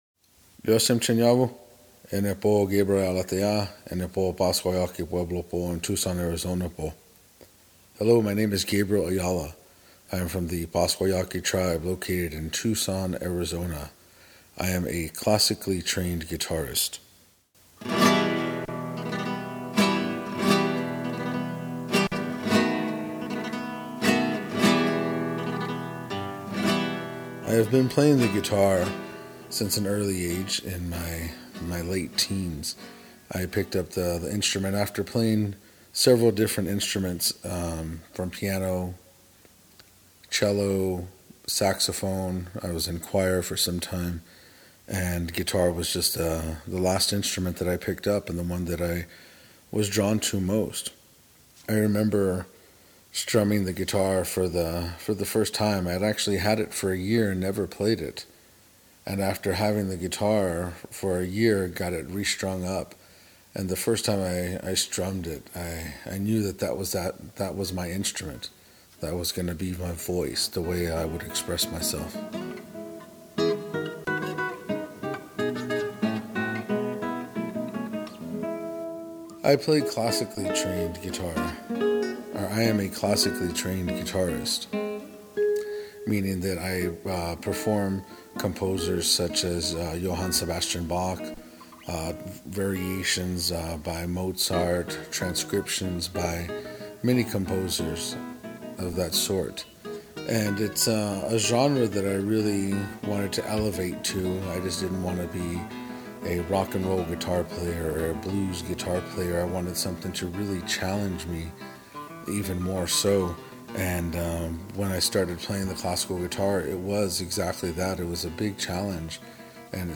Art Talk
the classical guitar.
Transcript of Guitarist